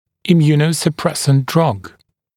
[ɪˌmjuːnəusə’presnt drʌg][иˌмйу:ноусэ’прэснт драг]иммунодепрессант, иммунодепрессивное средство